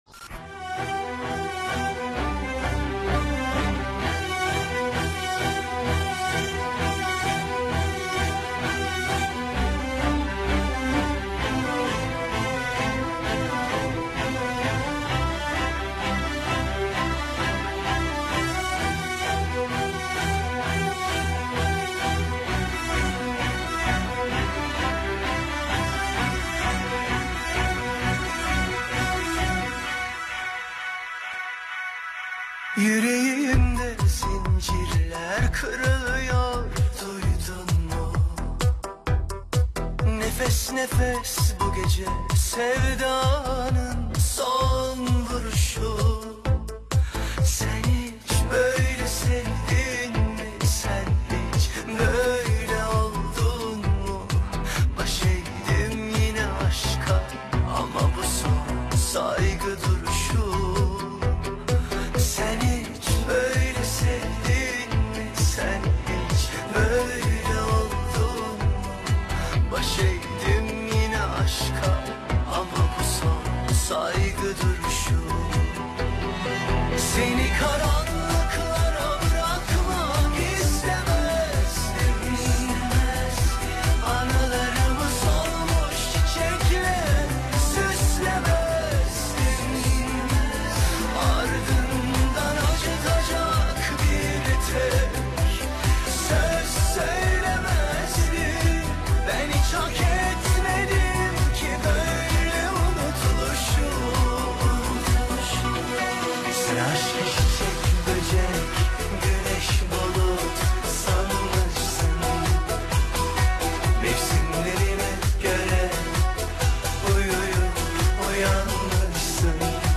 Pop, Dans, Balad